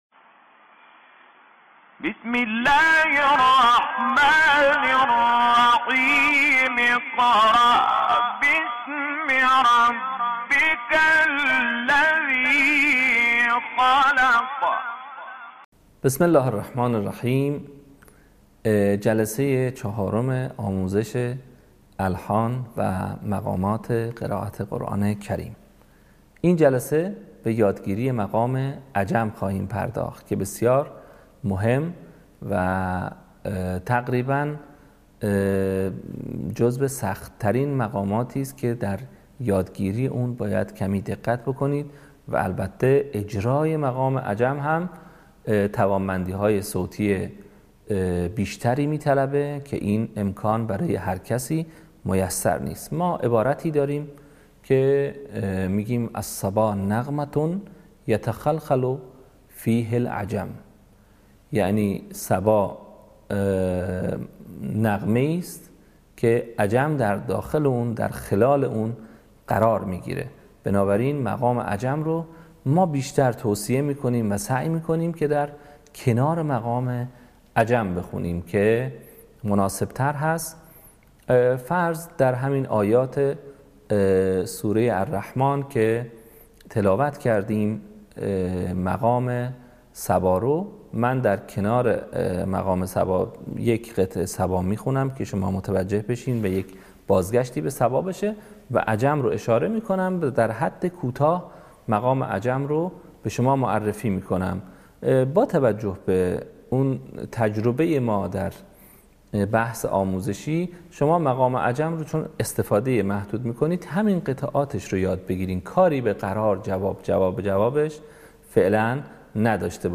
صوت | آموزش مقام عجم